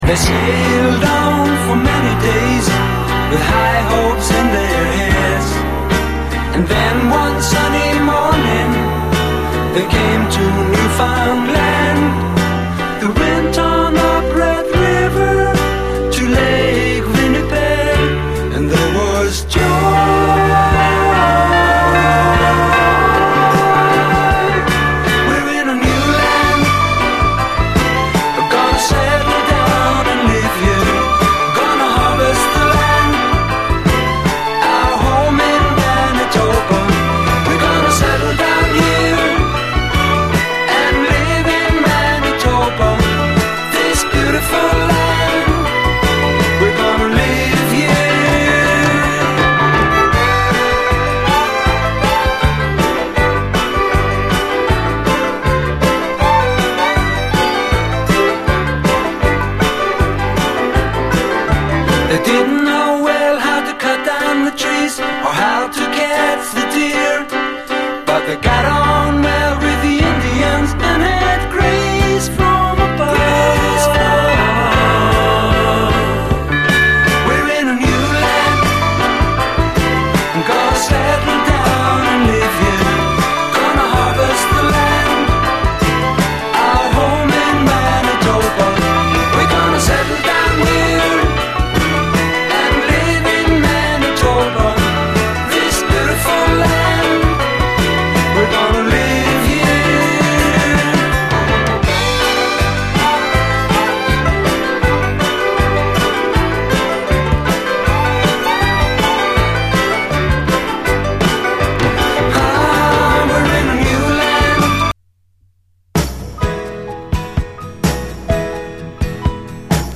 ドラム・ブレイクで始まるハッピー・トロピカル・ダンサー